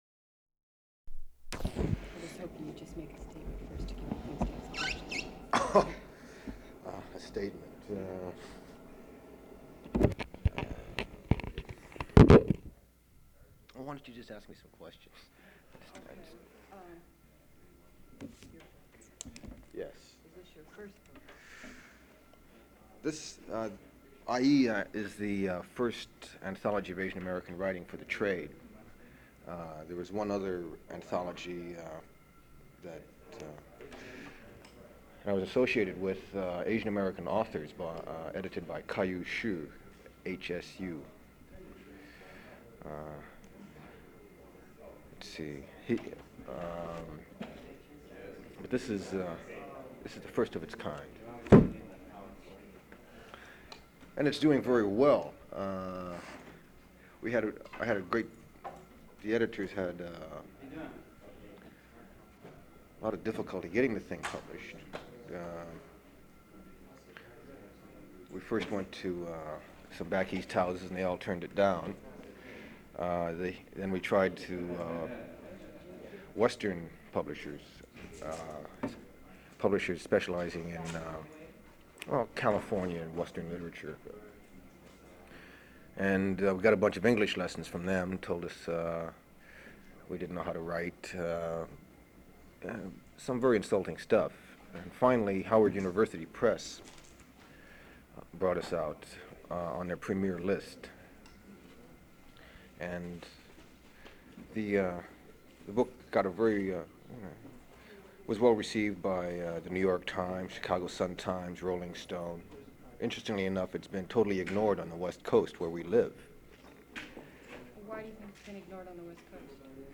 [00:00:20] The interview questions begin; interviewer asks about his Anthology, Aiiieeeee! An Anthology of Asian American Writers.
[00:20:22] Audio ends mid interview.
Form of original Open reel audiotape